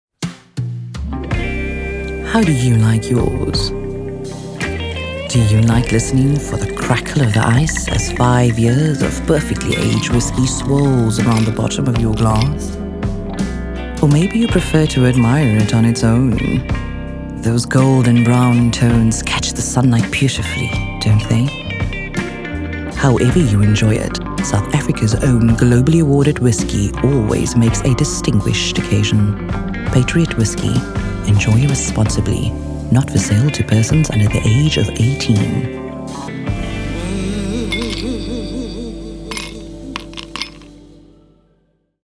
South Africa
clear, deep, suggestive, sultry
Soft-Sell | Serene, Sexy, Deep voice